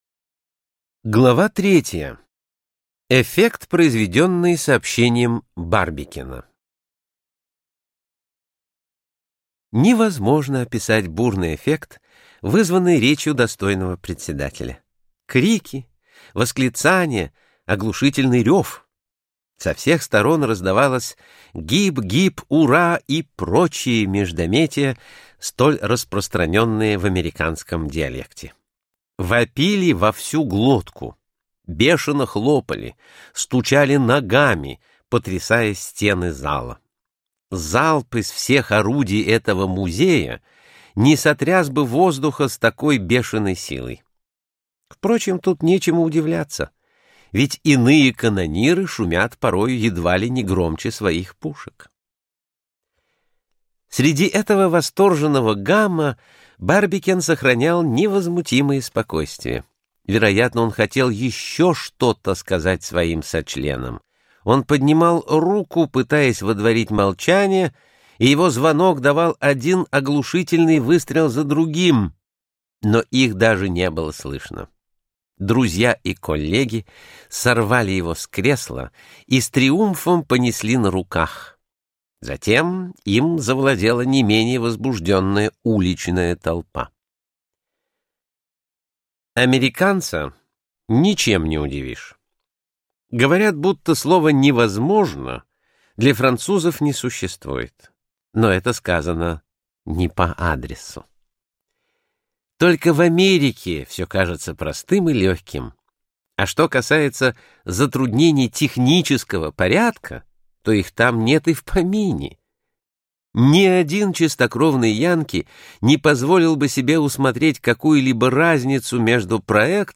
Аудиокнига С Земли на Луну прямым путем за 97 часов 20 минут - купить, скачать и слушать онлайн | КнигоПоиск